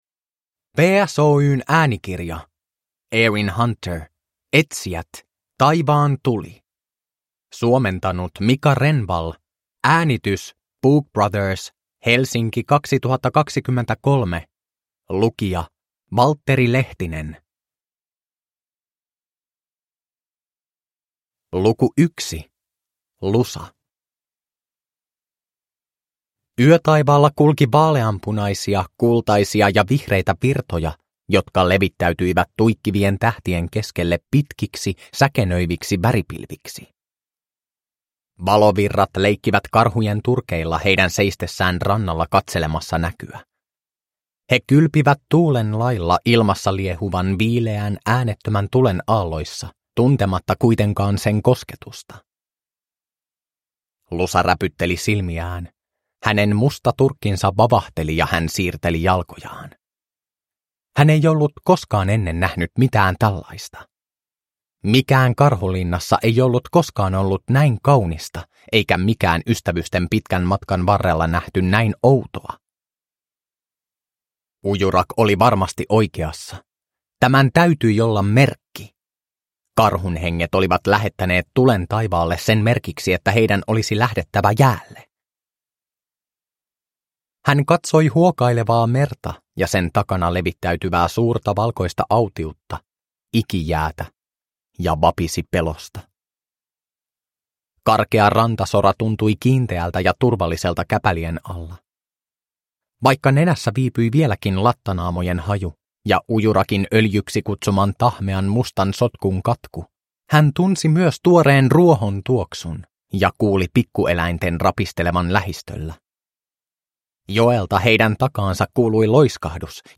Etsijät: Taivaan tuli – Ljudbok – Laddas ner